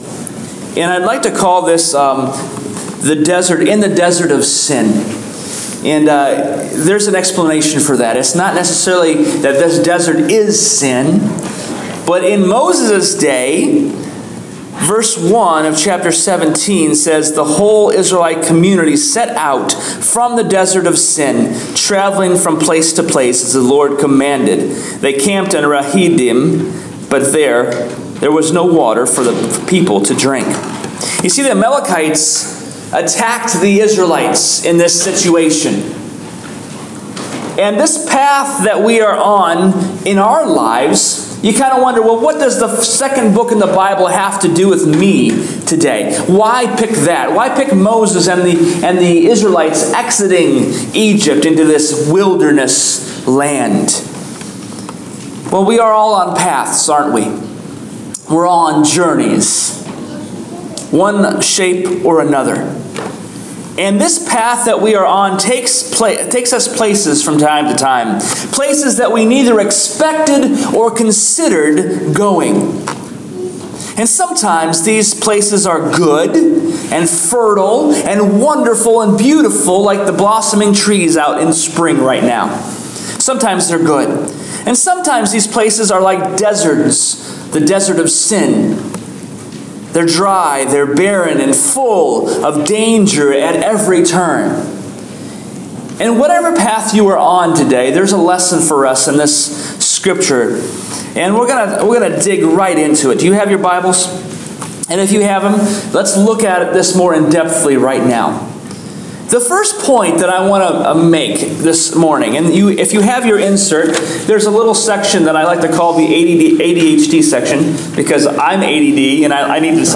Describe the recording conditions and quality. Listen to our Sermon from The Evansville Salvation Army.